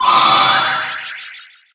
Cri de Coléodôme dans Pokémon HOME.